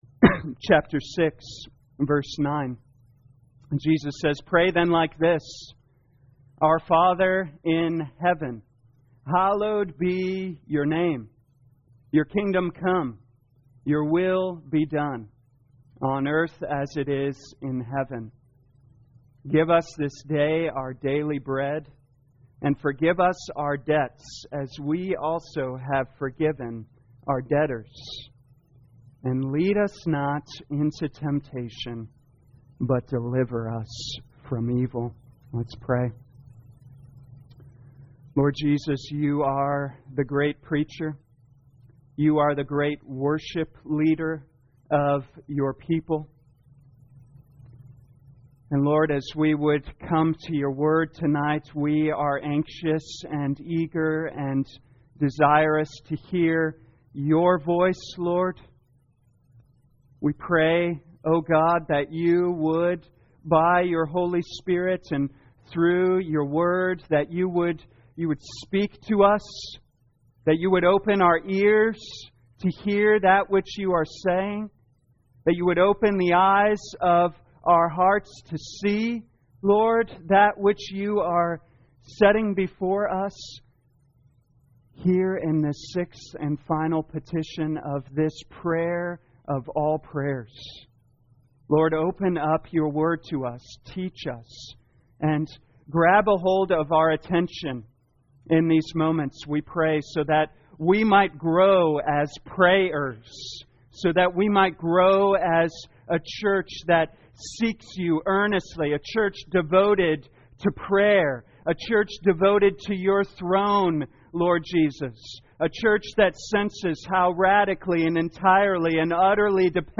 2021 Matthew Prayer Evening Service Download